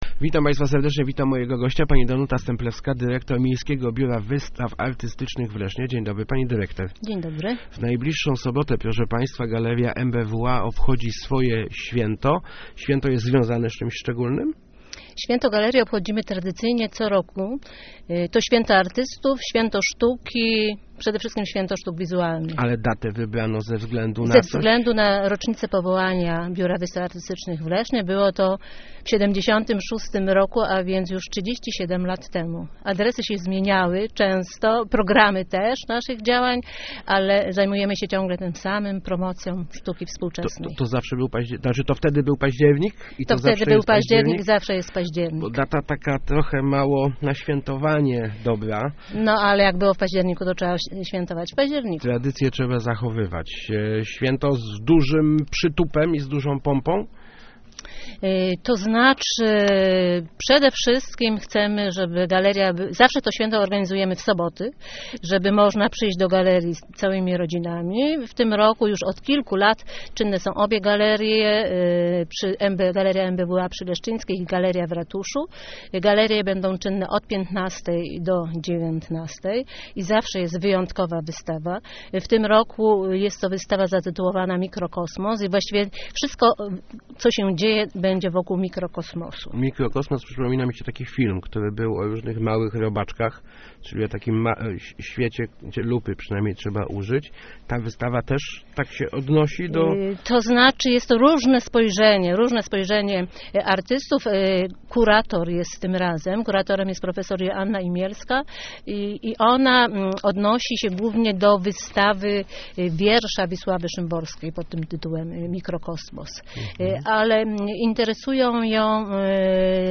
11.10.2013. Radio Elka